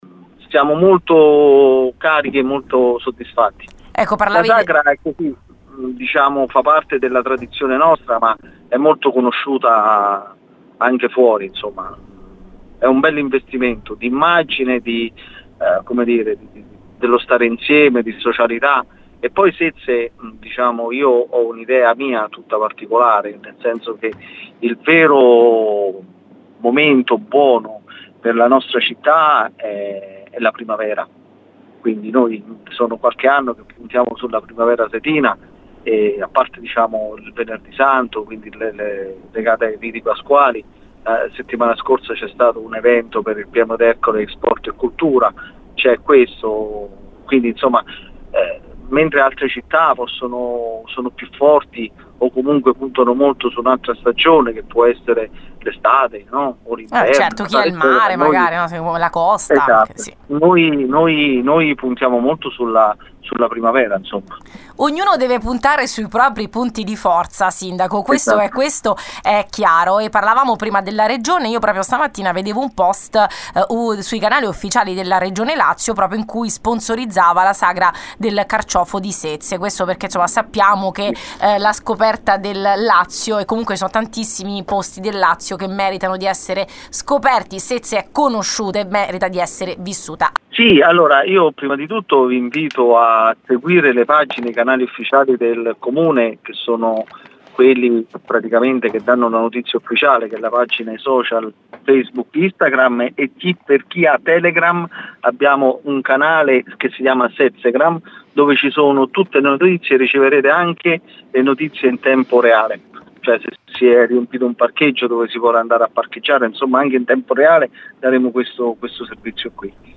Sezze pronta per la Sagra del Carciofo: l’intervista al Sindaco Lucidi